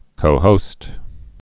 (kōhōst)